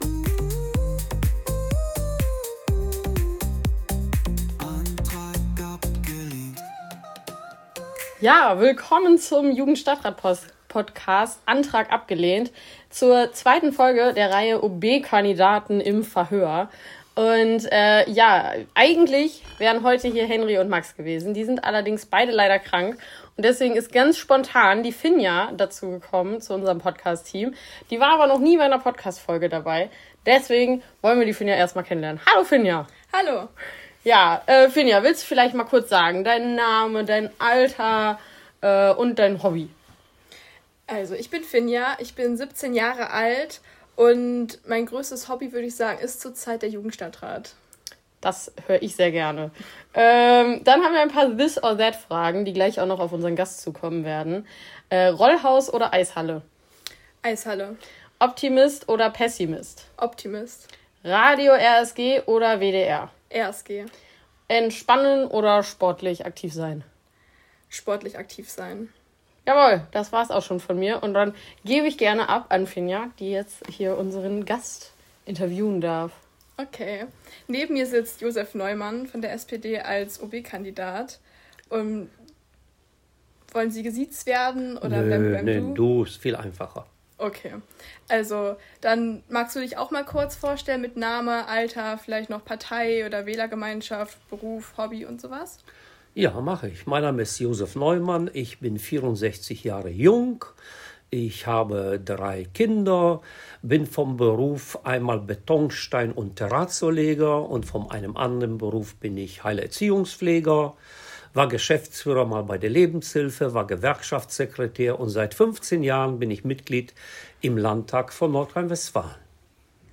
Heute mit Josef Neumann der SPD. Jugendkultur, Mobilität, Gleichberechtigung und Klimaschutz - wir wollen wissen wofür die OB-Kandidaten sich einsetzen wollen!